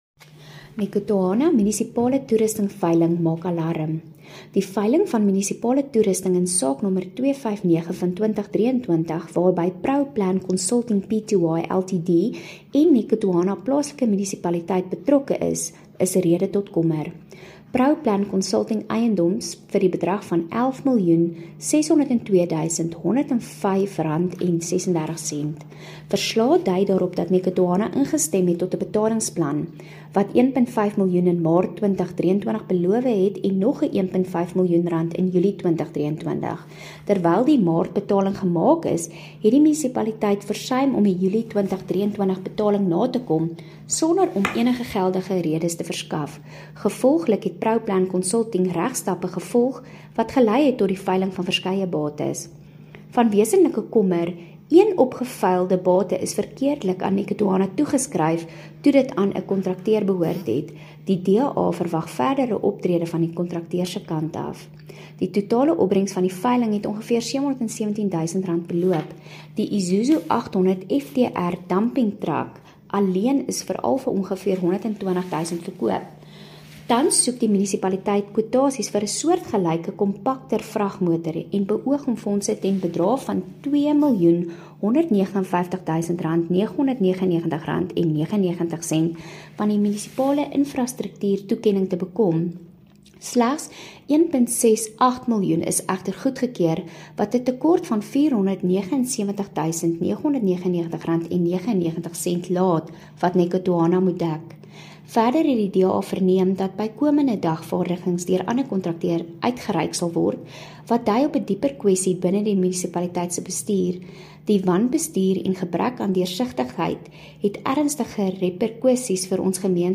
Afrikaans soundbite by Cllr Anelia Smit,